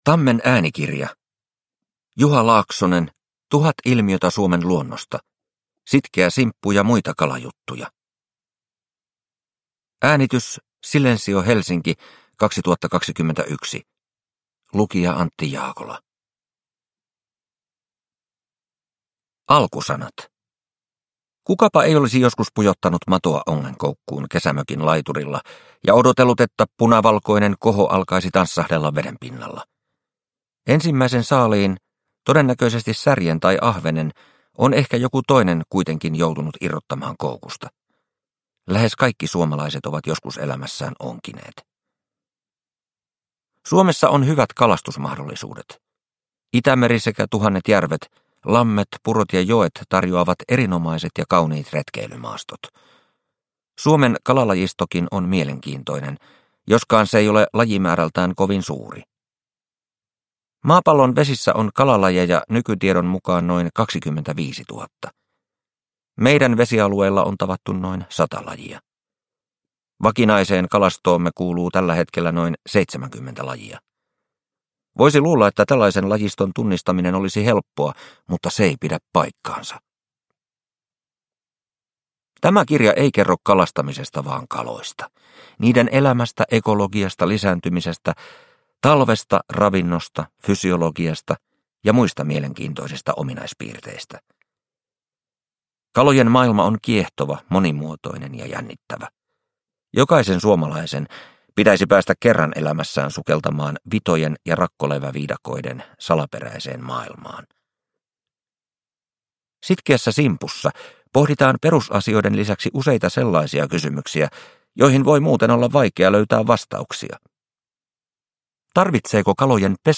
Sitkeä simppu ja muita kalajuttuja – Ljudbok – Laddas ner